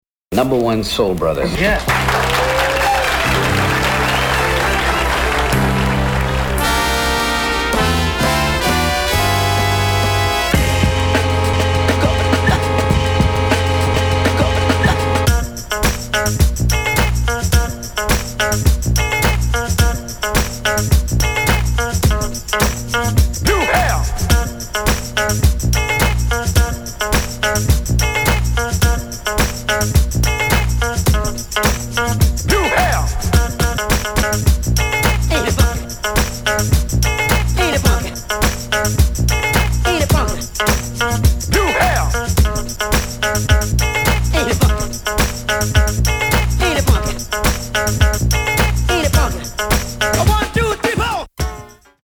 ジャンル(スタイル) JAPANESE HIP HOP